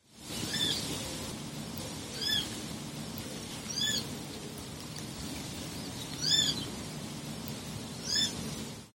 Varpushaukan tavallisiin ääniin kuuluu käheänkimakka vihellys.
varpushaukka.mp3